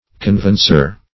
Conveyancer \Con*vey"an*cer\ (k[o^]n*v[=a]"an*s[~e]r), n. (Law)